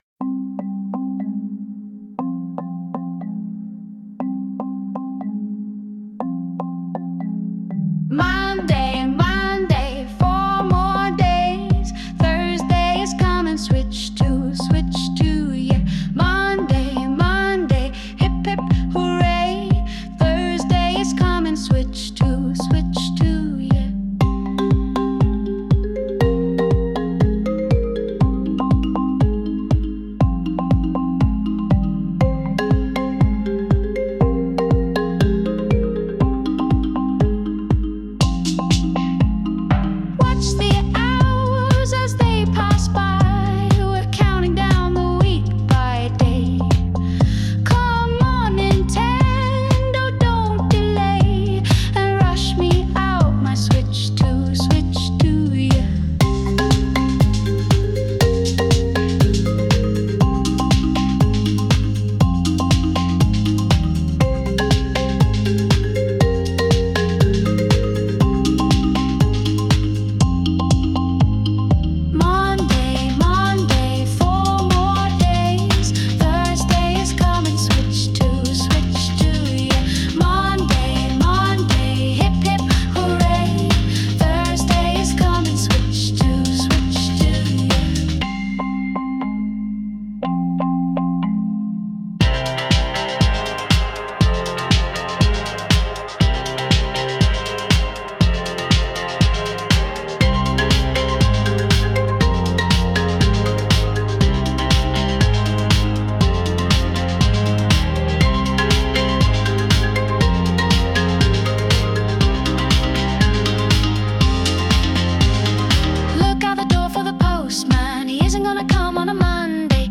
Sound Imported : Indian Llama
Sung by Suno